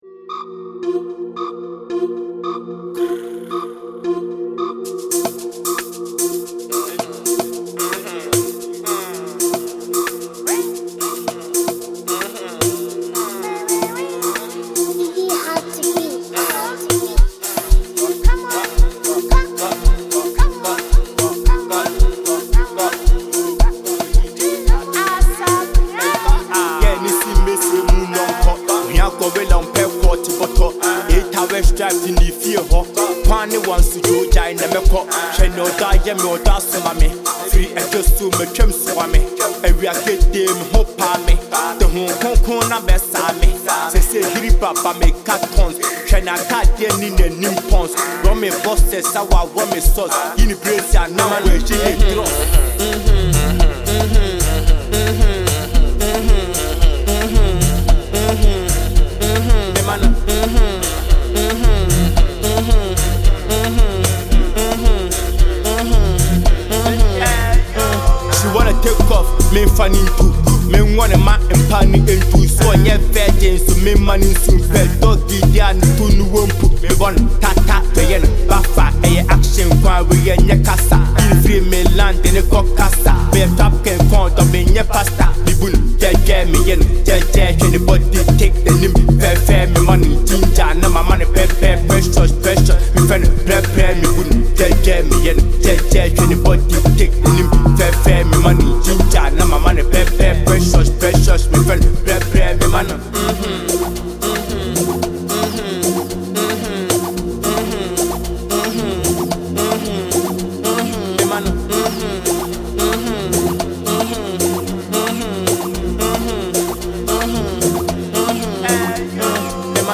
Ghanaian rapper
is driven by a dark, bass-heavy instrumental
aggressive verses filled with confidence and street realism
blending drill elements with crisp sound design.